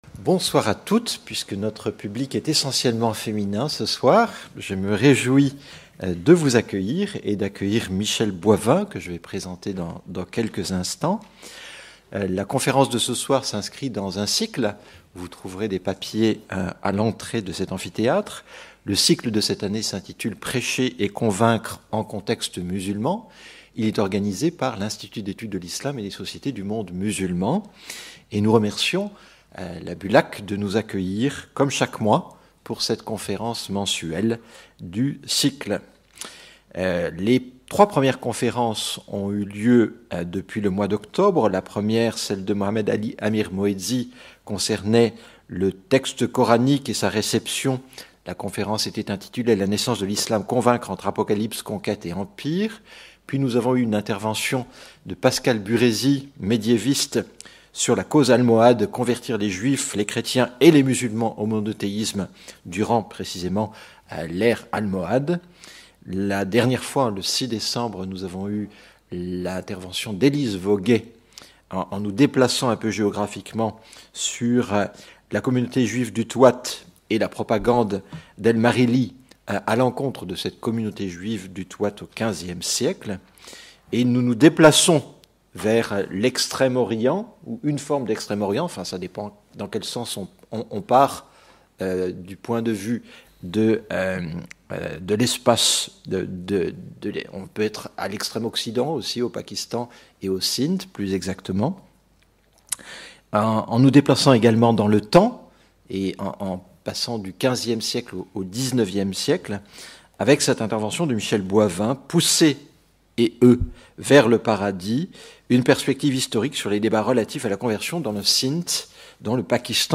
Conférences publiques de l’Institut d’études de l’Islam et des sociétés du monde musulman (IISMM) en partenariat avec la Bibliothèque universitaire des langues et civilisations (BULAC)